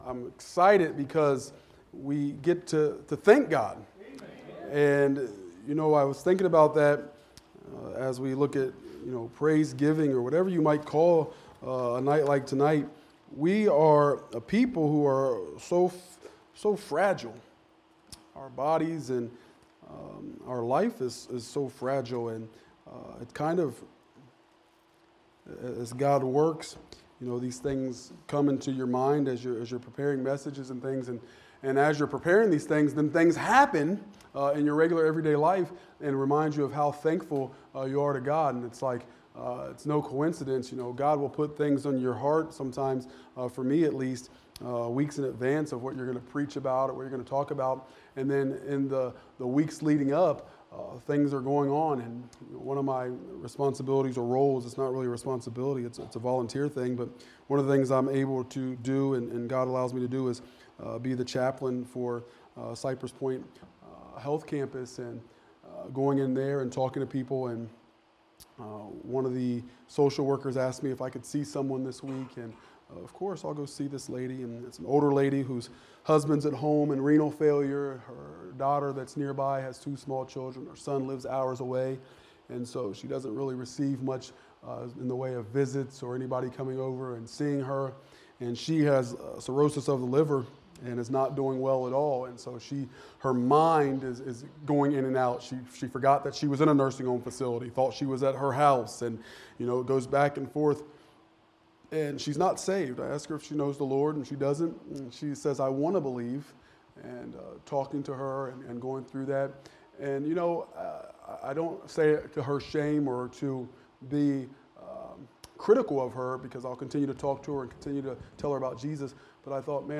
Prasiegiving Service